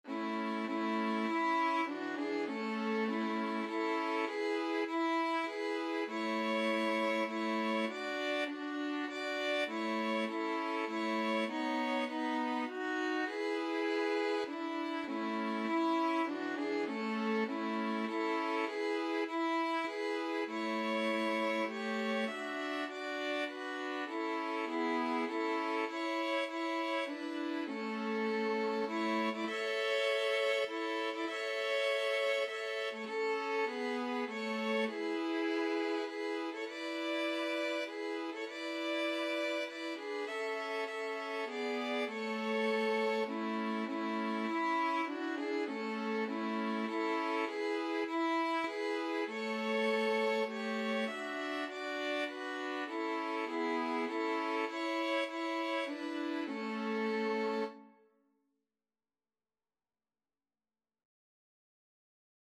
Free Sheet music for 2-violins-viola
3/4 (View more 3/4 Music)
A major (Sounding Pitch) (View more A major Music for 2-violins-viola )
Traditional (View more Traditional 2-violins-viola Music)